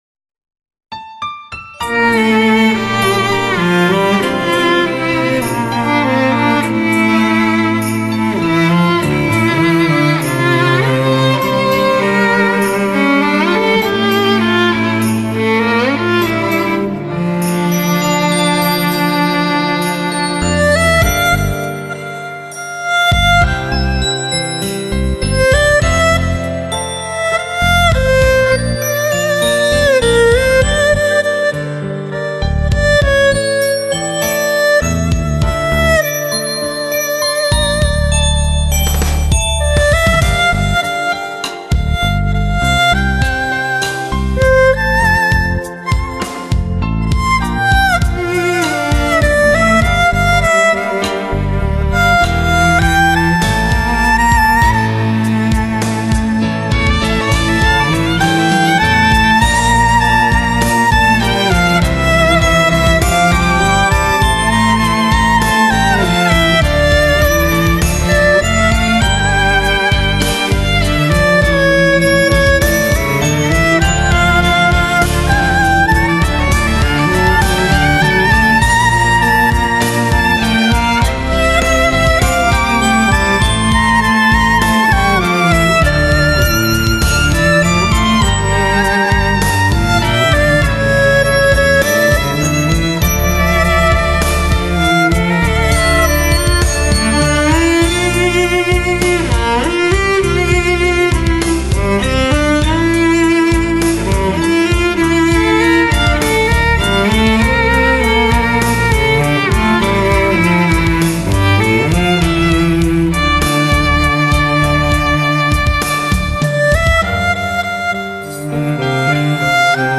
首次大胆尝试用纯正、传统的中国民族乐器二胡演绎现代时尚，经典的流行音乐，让听者别有一番滋味。
整张专集汇集了国内顶尖录音师，同时邀请了一流的乐队担任弦乐伴奏，音乐制作清新唯美。